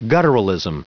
Prononciation du mot gutturalism en anglais (fichier audio)
Prononciation du mot : gutturalism